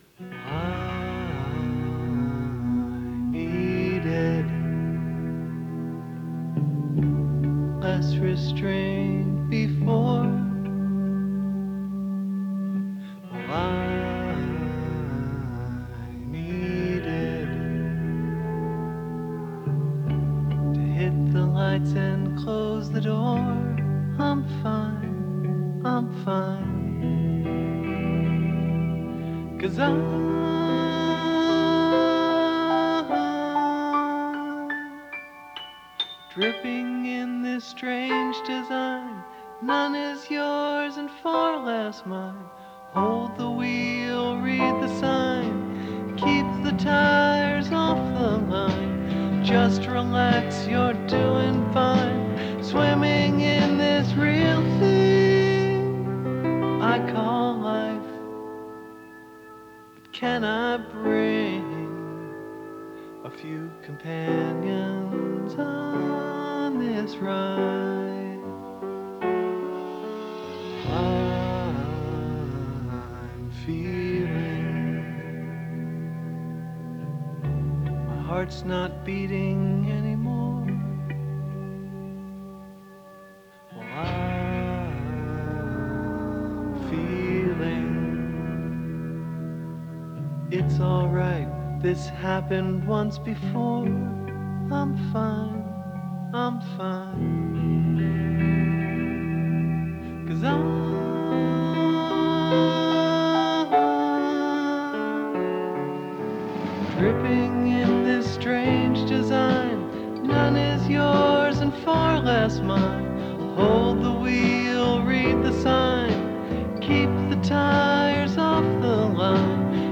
Studio Version 1995: